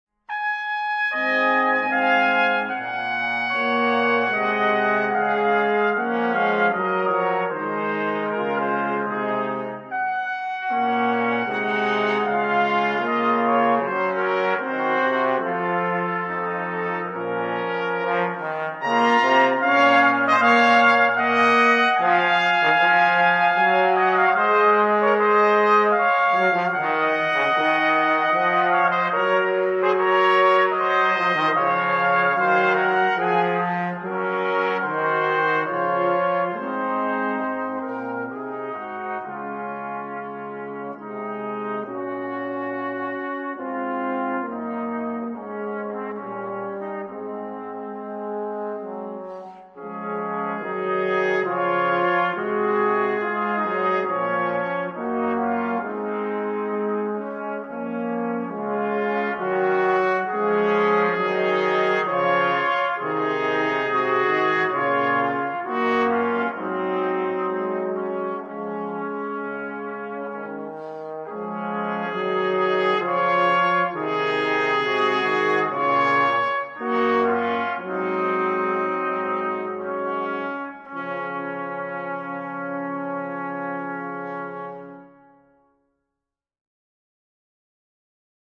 Gattung: für Blechbläserquartett
Besetzung: Ensemblemusik für 4 Blechbläser